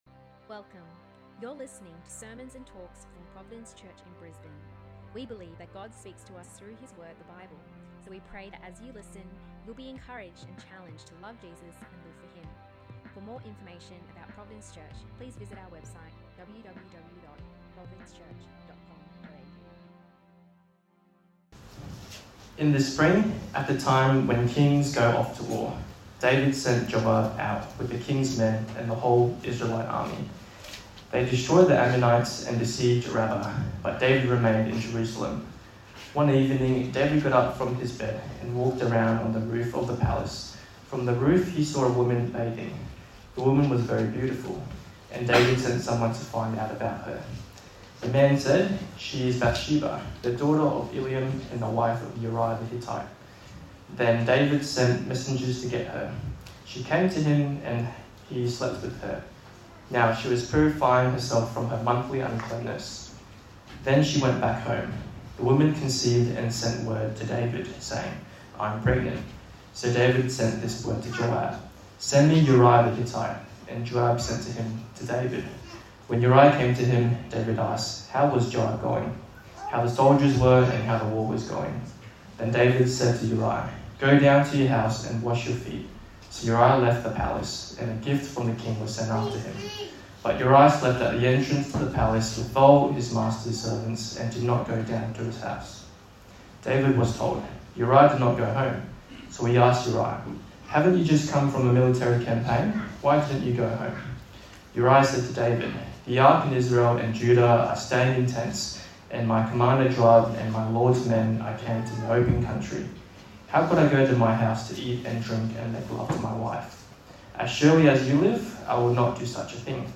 A message from the series "Rise & Fall."